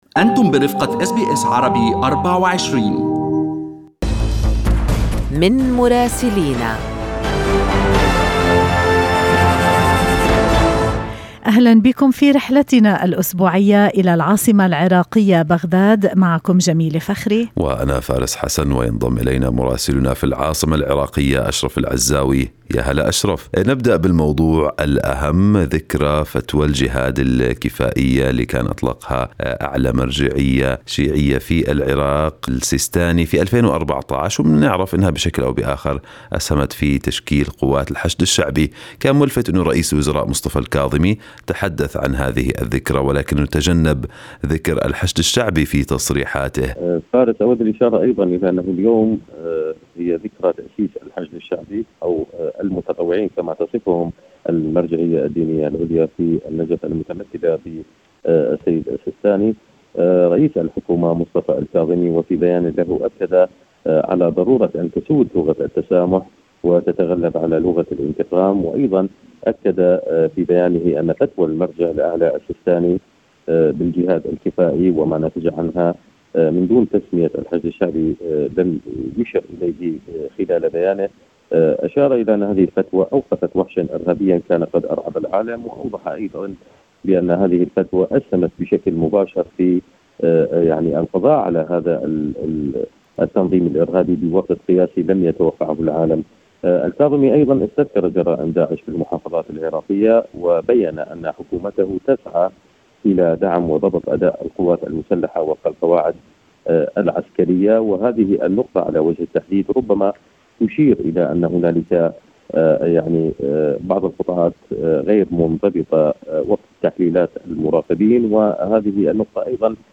من مراسلينا: أخبار العراق في أسبوع 18/6/2021